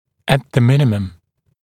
[æt ðə ‘mɪnɪməm][эт зэ ‘минимэм]как минимум